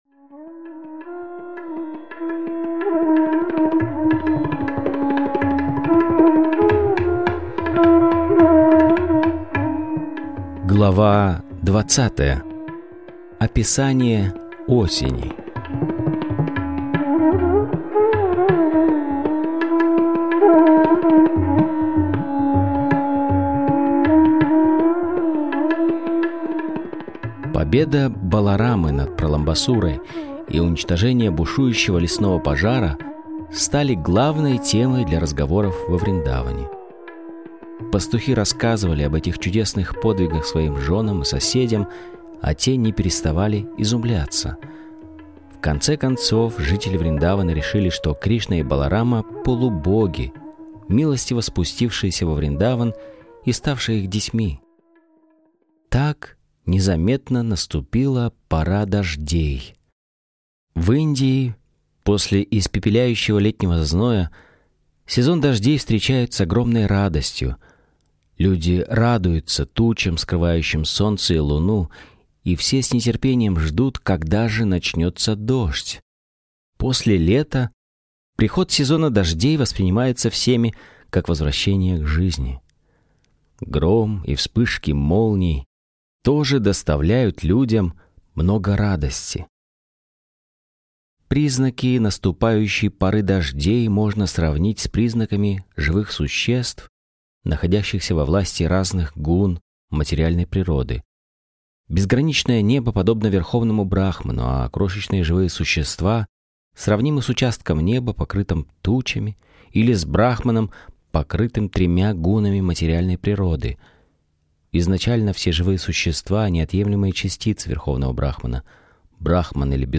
Источник вечного наслаждения автор Абхай Чаран Де, Бхактиведанта Свами Прабхупада Информация о треке Автор аудиокниги : Абхай Чаран Де Бхактиведанта Свами Прабхупада Аудиокнига : Кришна.